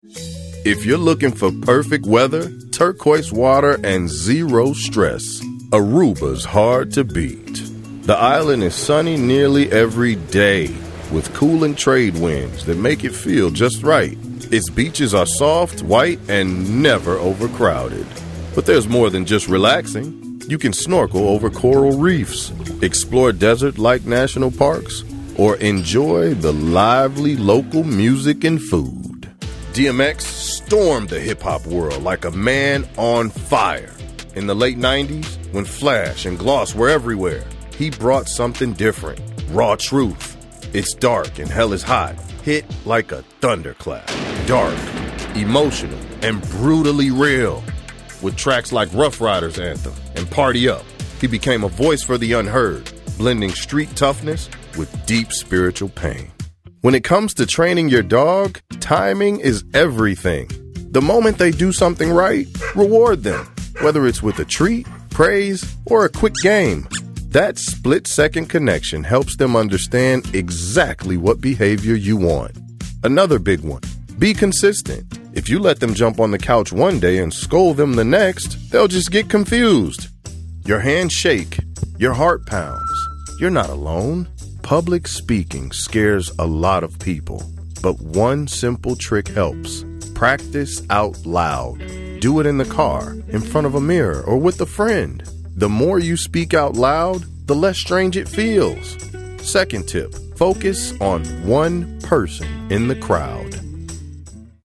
Authentic, confident, honest, treating the audience like family, with a strong, yet compassionate voice.
This demo is a mash up displaying vocal range and VO narrative abilities alike.